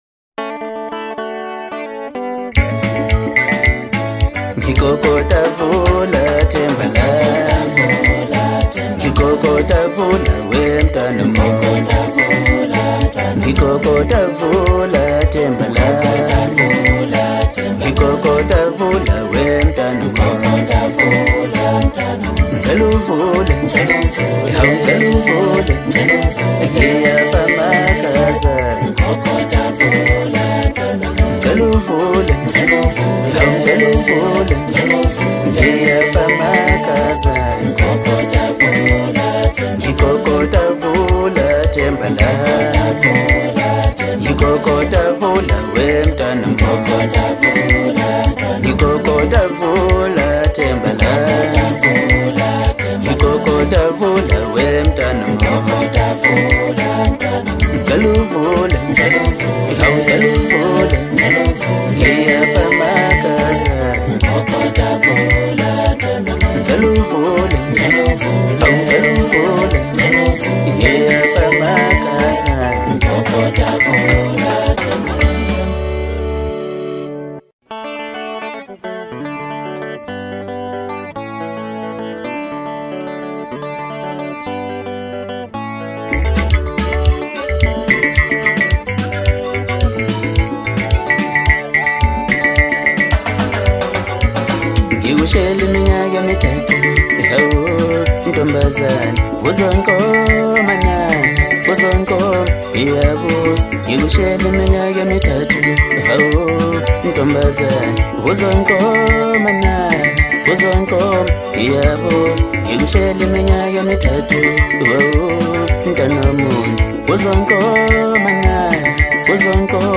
Home » Maskandi » DJ Mix
South African Maskandi singer